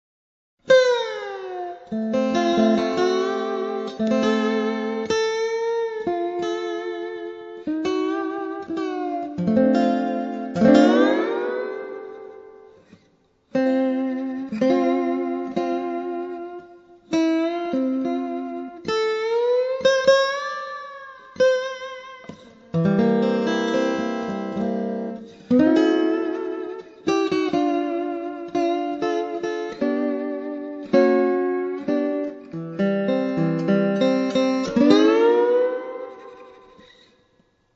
Steel Guitar
(solo-6,10,15), Hawaiian Guitar